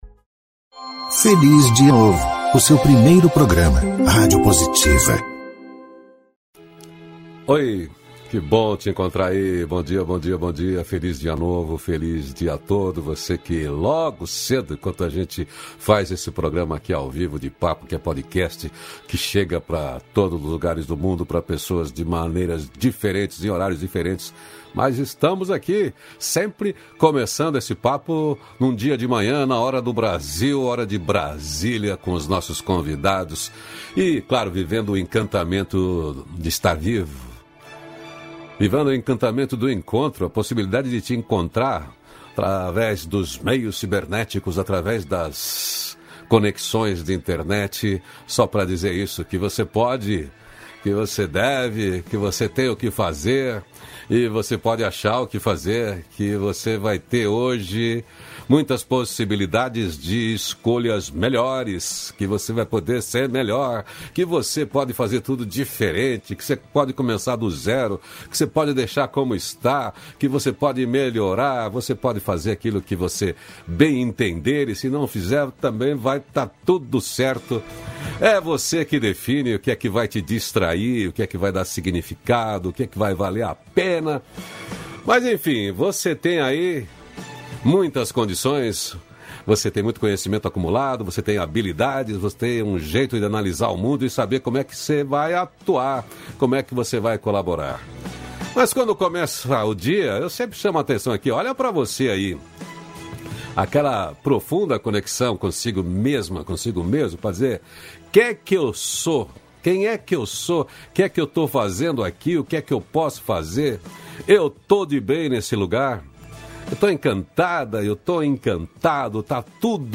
Diálogo Nutritivo com José Luiz Tejon. Ele antecipa aqui o lançamento do seu mais novo livro, “ O poder do Incômodo”.